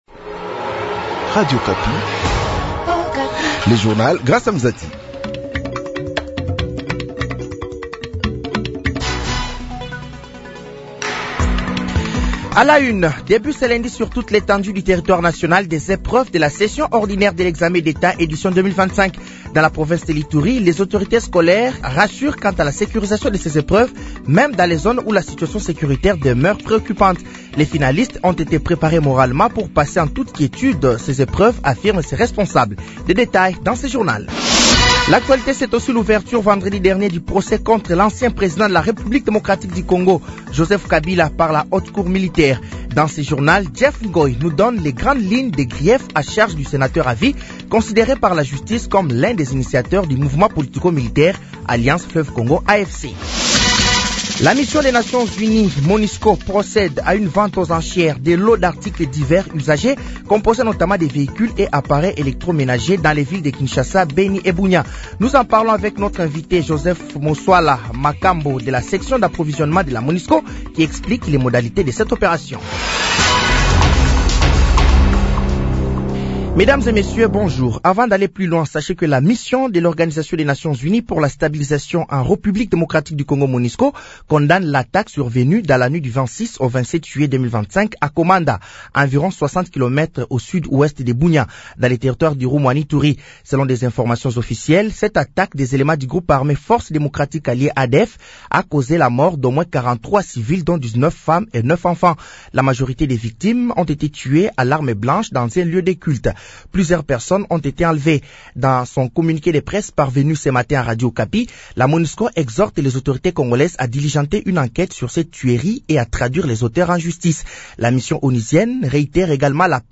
Journal français de 6h de ce lundi 28 juillet 2025